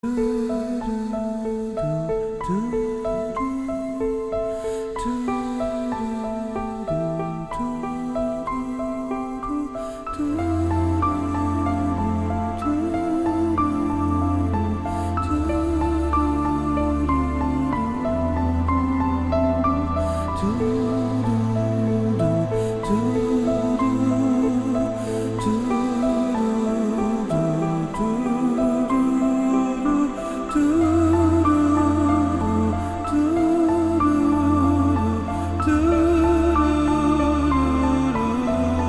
Грустные и красивые рингтоны MP3 для мобильных [61]